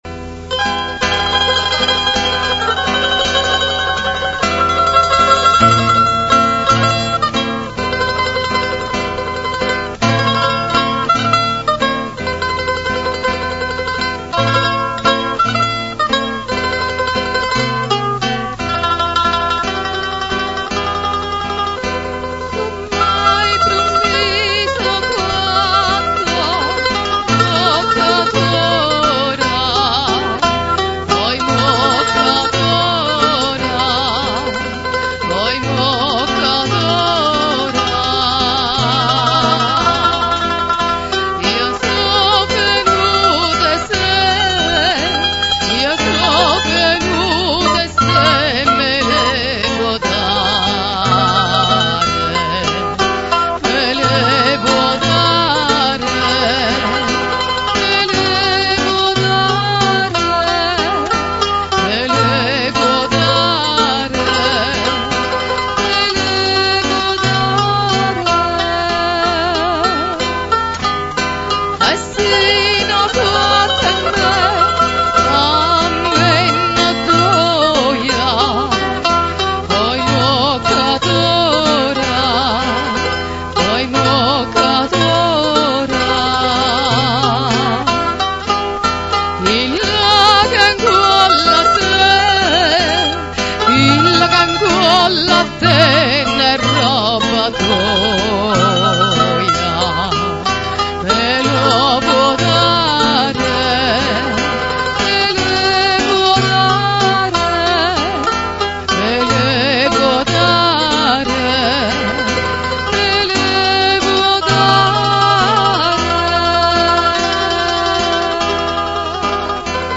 Ascolta Classiche vesuviane cantate da " Napoli Antica" Se non si ascolta subito il suono attendere qualche secondo, solo la prima volta